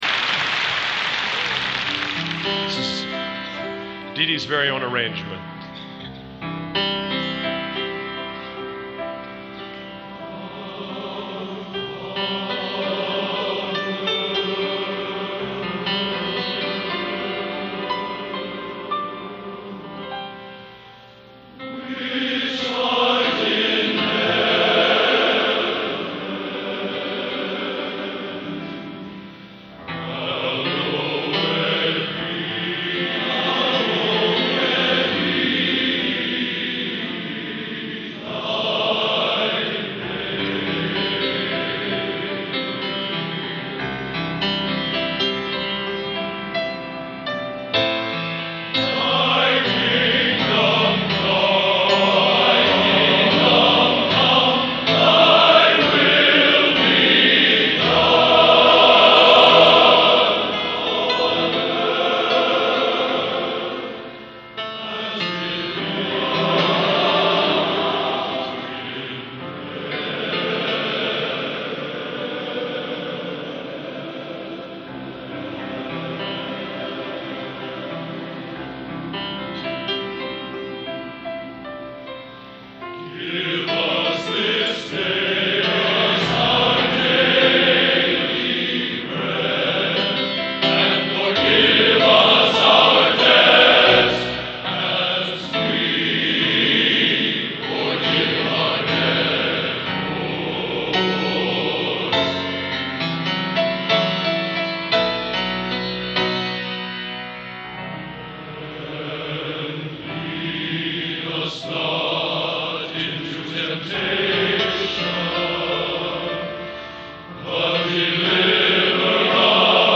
Genre: Sacred | Type: